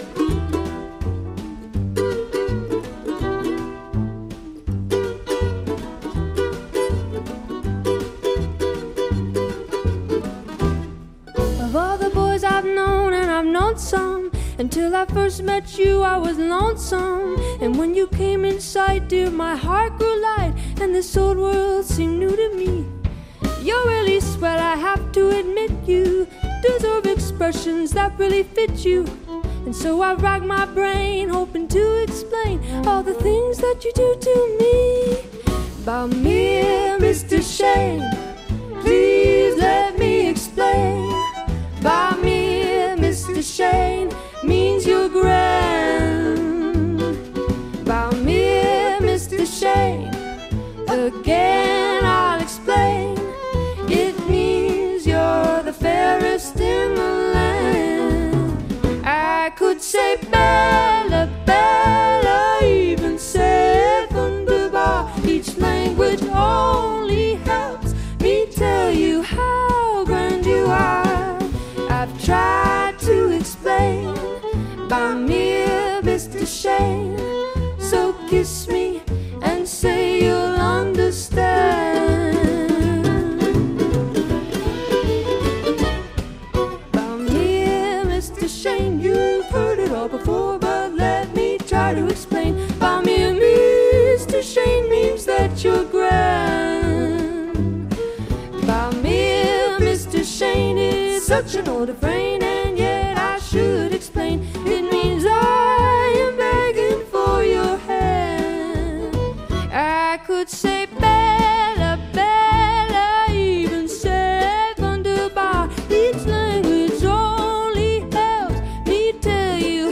jazz music موسیقی جاز